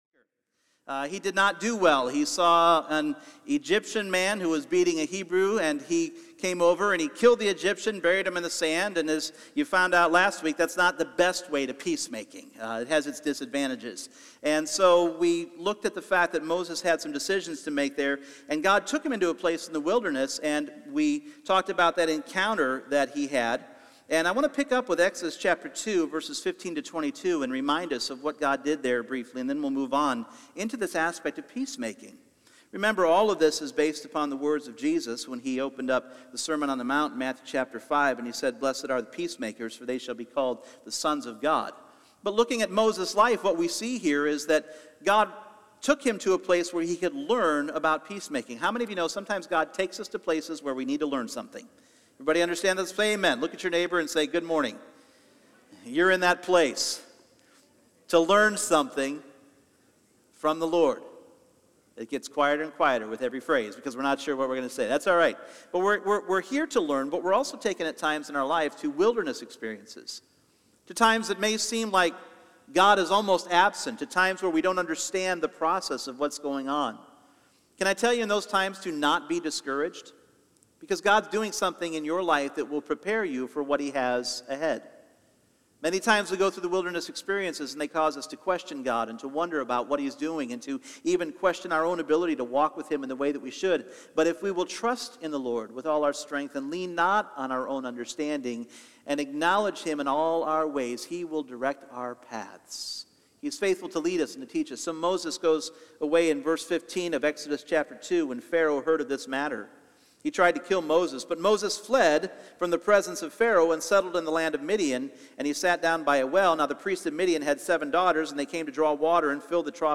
Individual Messages Service Type: Sunday Morning Blessed are the peacemakers...